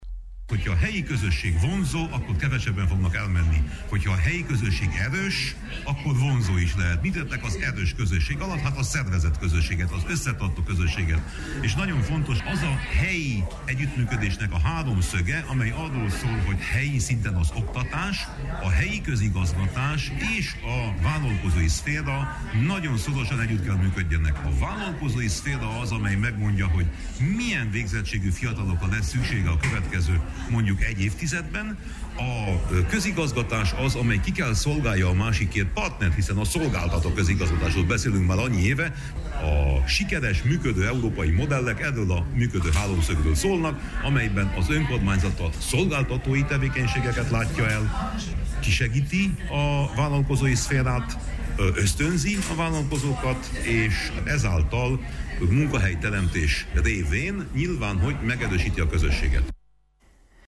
Brüsszeli riport: A fiatalok hazatérését kellene ösztönözni
Európai és tagállami szinten is intézkedéseket kell hozni a képzett fiatalok elvándorlásának visszaszorítása érdekében, fejtette ki Winkler Gyula, Európai parlamenti képviselő Brüsszelben, az Európai Városok és Régiók hete alkalmával szervezett konferencián.